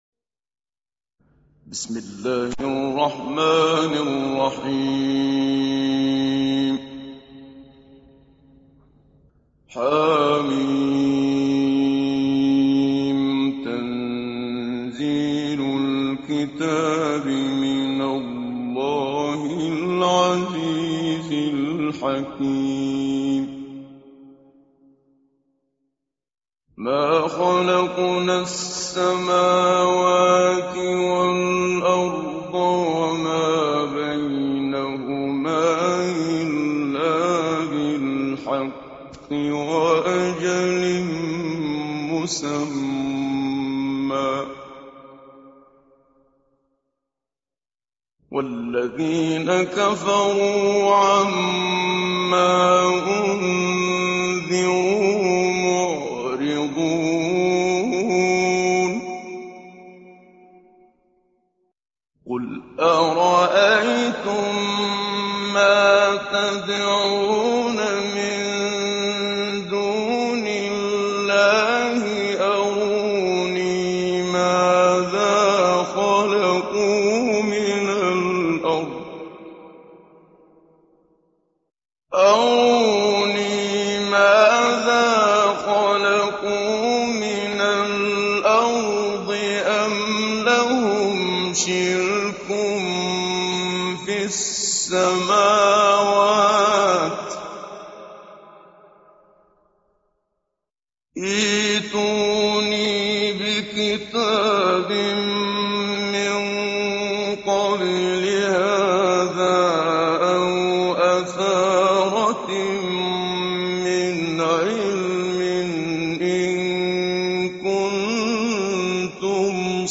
تحميل سورة الأحقاف mp3 محمد صديق المنشاوي مجود (رواية حفص)
تحميل سورة الأحقاف محمد صديق المنشاوي مجود